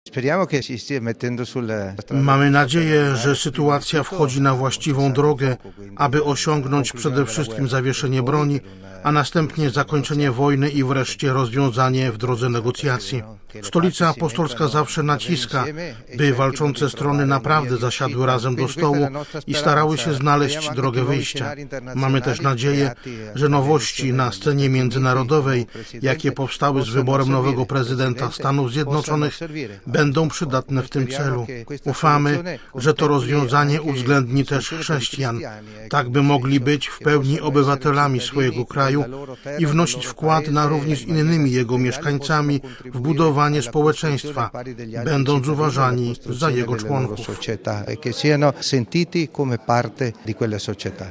Odbył się on w dniach 11-13 listopada w Rzymie w ramach obchodów stulecia Papieskiego Instytutu Studiów Wschodnich.
Kard. Pietro Parolin mówi o oznakach nadziei.